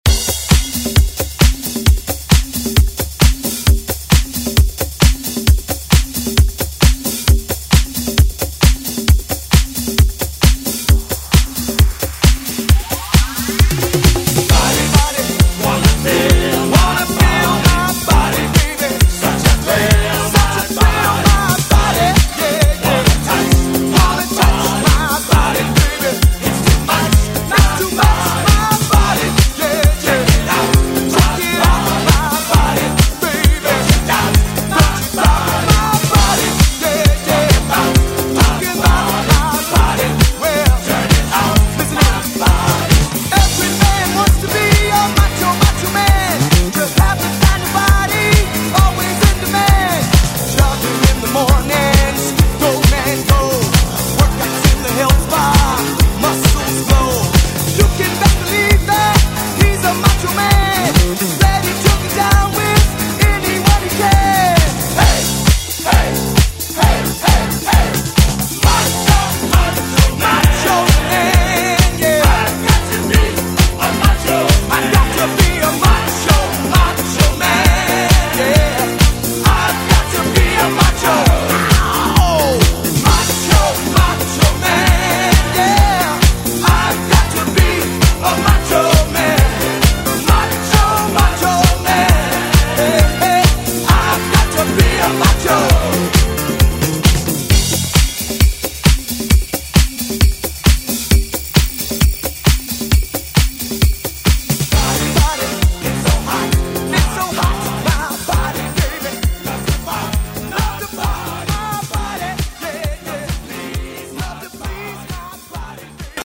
Electronic Funk Soul Disco Music
133 bpm